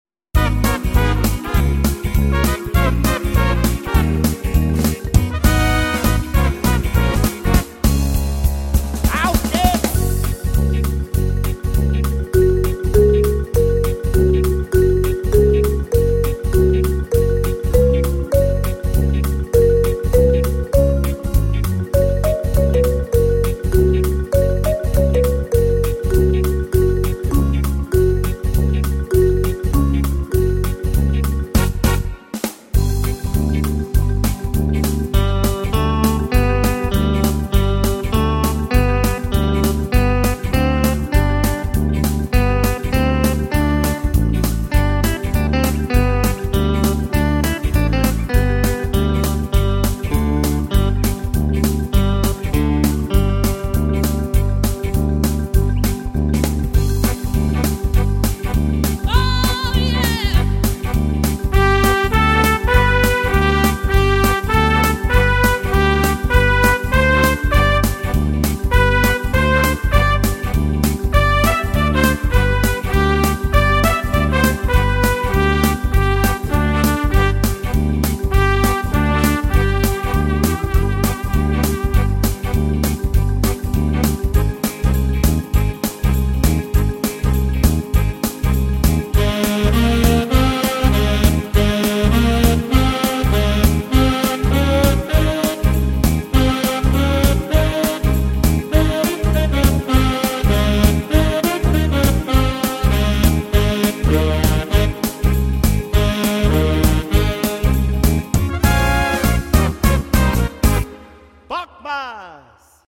I play this song in several tonalities